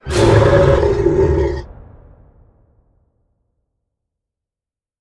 Jumpscare_Monster.wav